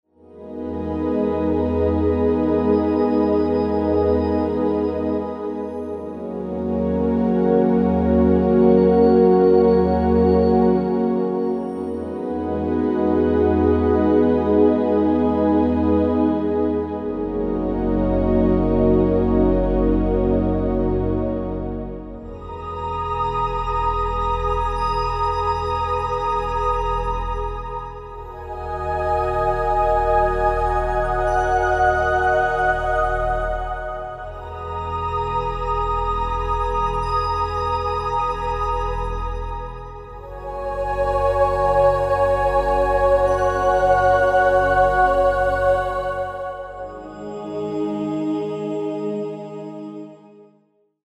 Produced from a newly mixed 24-bit/96kHz stereo master